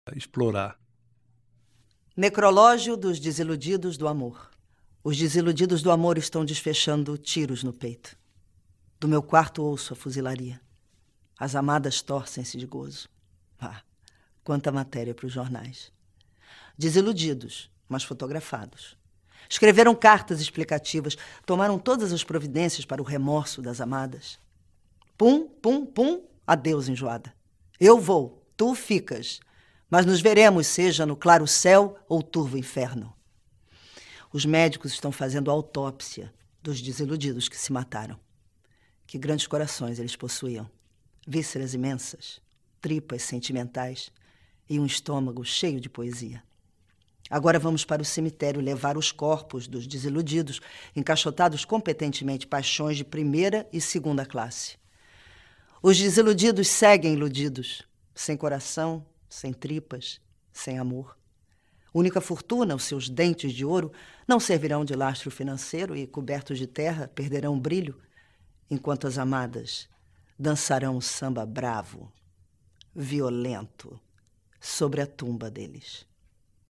Narrado por Fernanda Torres.